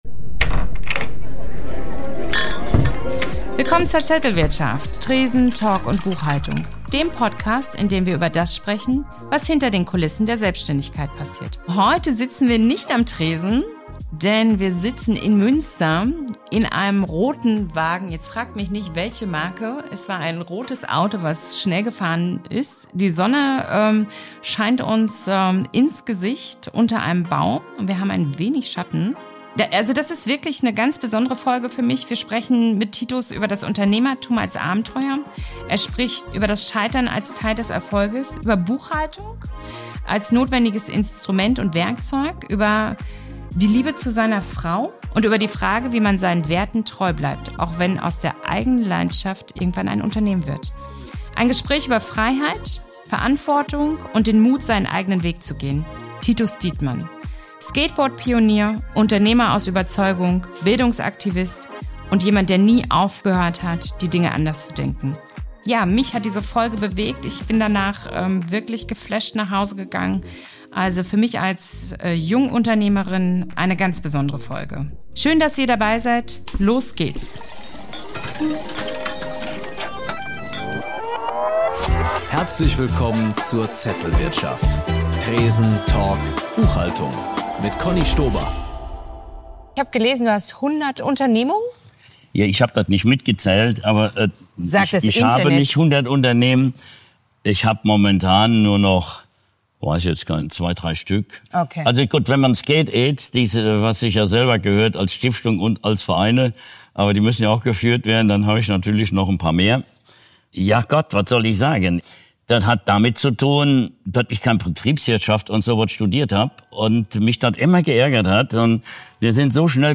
Keine Studiolampen, kein Skript, kein Zettel – sondern ein echtes Gespräch, live bei Titus Dittmann zu Hause.